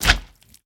Minecraft Version Minecraft Version 1.21.4 Latest Release | Latest Snapshot 1.21.4 / assets / minecraft / sounds / mob / magmacube / big4.ogg Compare With Compare With Latest Release | Latest Snapshot